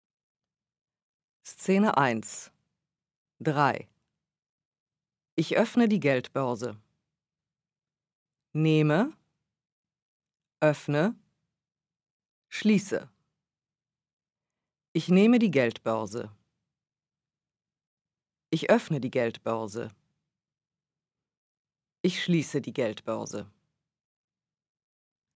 Audio recordings of each series by a native speaker are included with the book as a digital download, so you can hear the correct pronunciations from the beginning.